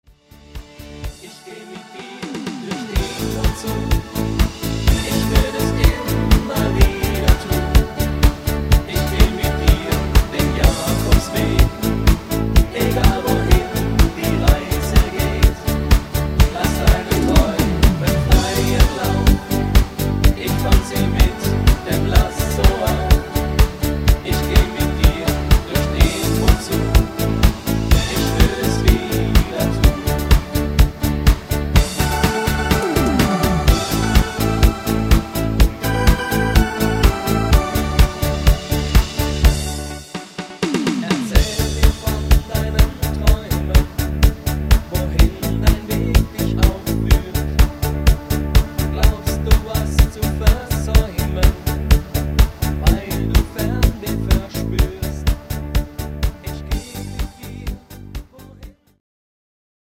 Rhythmus  Discofox
Art  Deutsch, Popschlager, Schlager 2020er